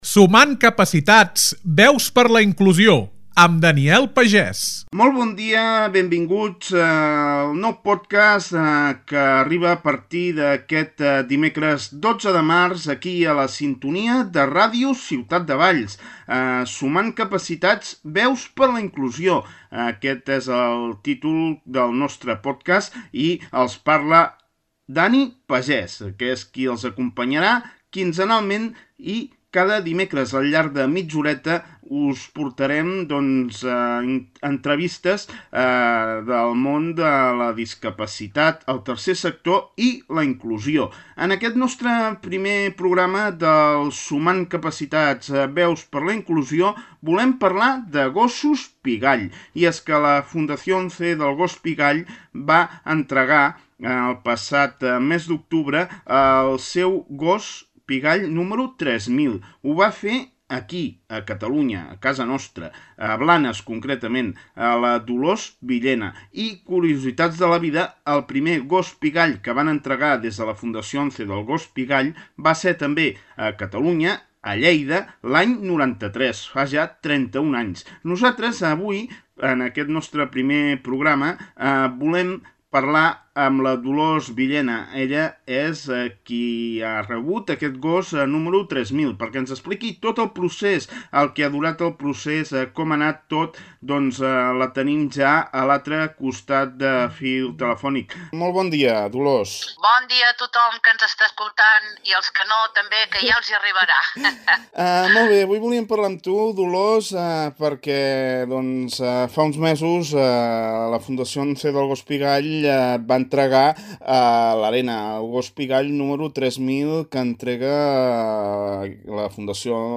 Un espai d’entrevistes sobre el món de la discapacitat, la inclusió i el Tercer Sector.